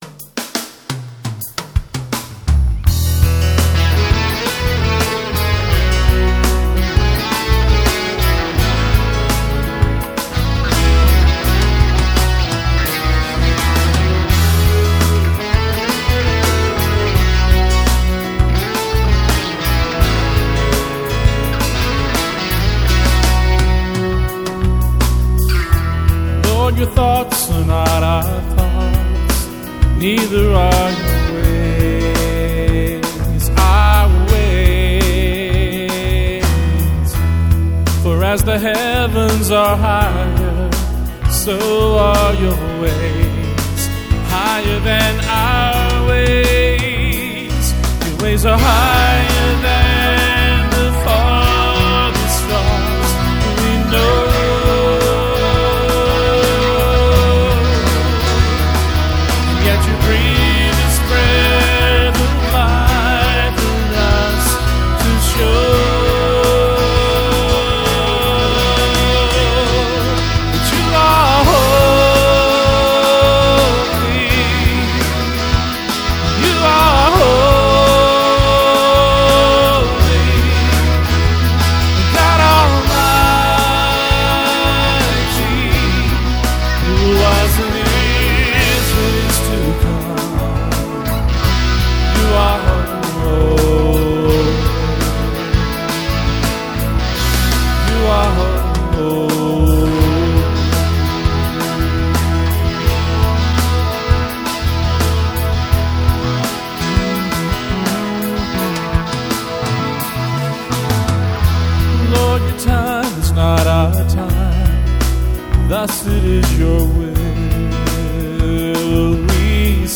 ASR-10/Peavey Bass